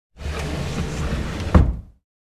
Moving-wooden-heavy-object-2.mp3